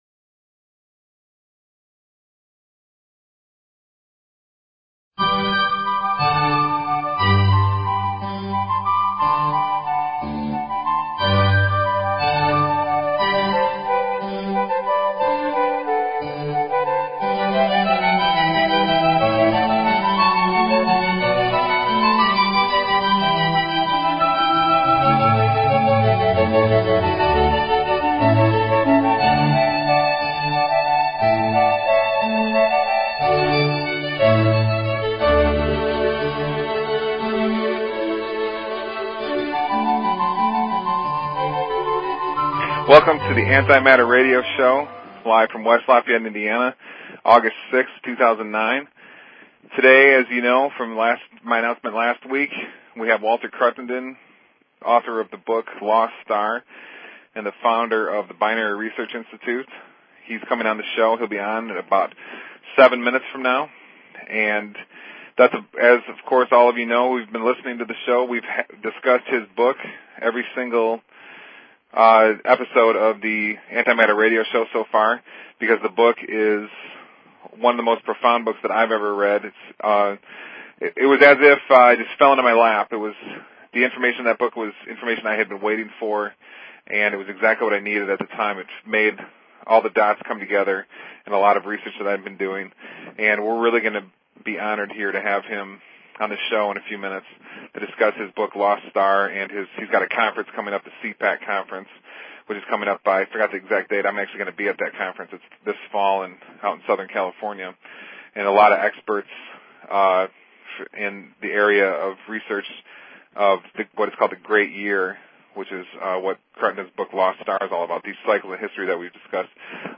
Talk Show Episode, Audio Podcast, The_Antimatter_Radio_Show and Courtesy of BBS Radio on , show guests , about , categorized as